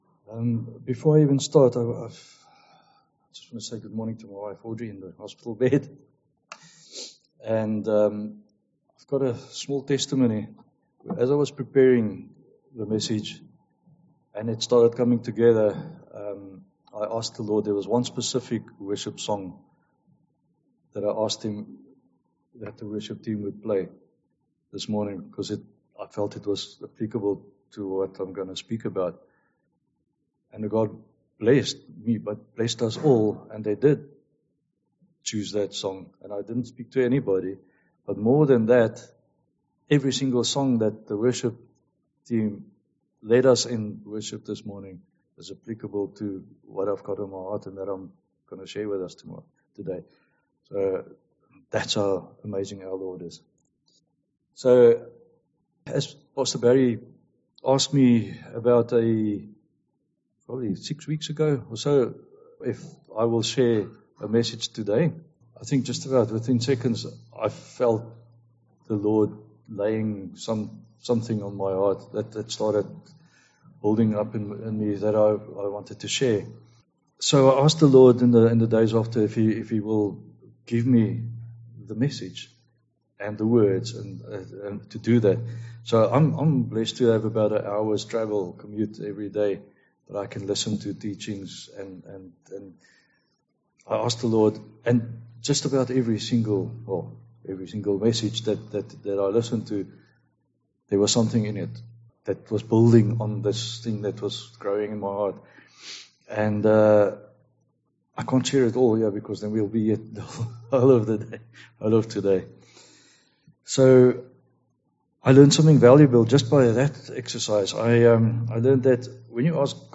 Series: Guest Speakers , Sunday morning studies Tagged with topical studies